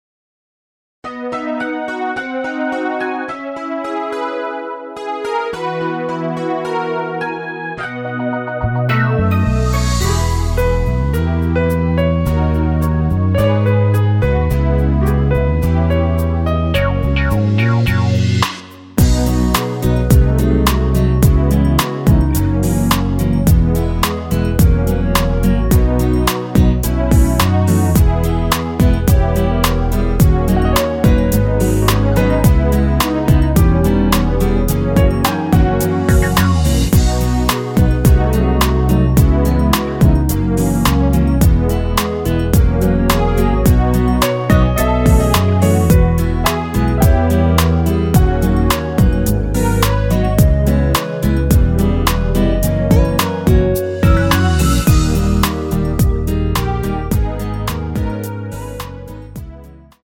원키에서 (-3) 내린 멜로디 포함된 MR 입니다.(미리듣기 참조)
앞부분30초, 뒷부분30초씩 편집해서 올려 드리고 있습니다.
중간에 음이 끈어지고 다시 나오는 이유는